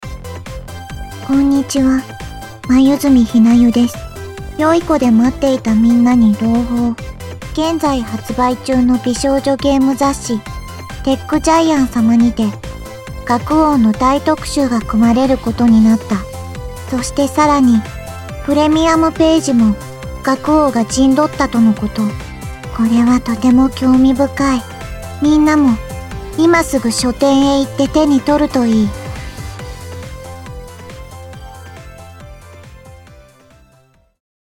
「学☆王- THE ROYAL SEVEN STARS -」TGプレミアム記念ボイスを公開しました！